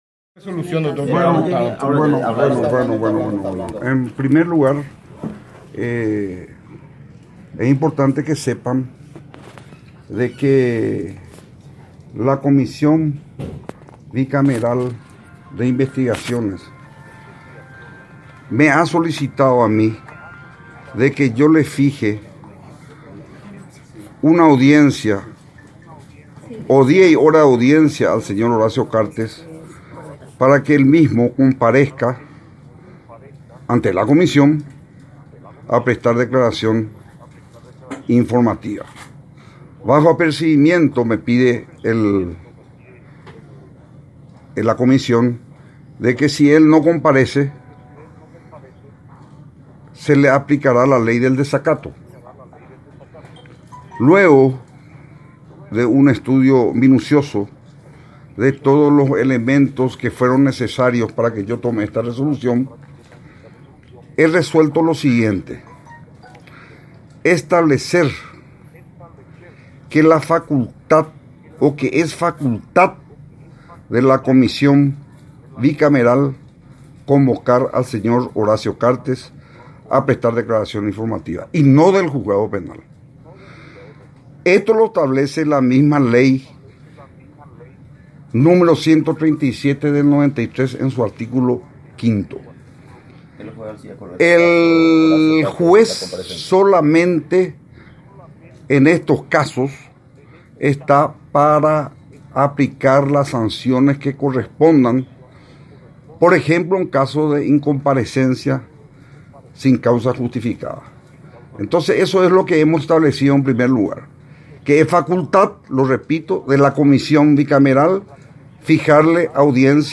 “Lo resuelto fue que se comunique a Horacio Cartes que, en el caso de no comparecer sin justa causa, será pasible a sanciones previstas en el artículo 5 de la ley 137/93, que debe ser aplicada por el Poder Judicial, vía Juzgado”, explicó Corbeta ante los medios de prensa, añadiendo que es atribución de la comisión llamar al expresidente.
10-JUEZ-ALCIDES-CORBETA.mp3